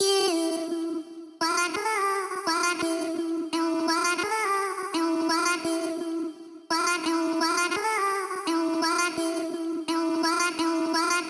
朴素的鹰叫声
标签： 170 bpm Rap Loops Vocal Loops 1.90 MB wav Key : D
声道立体声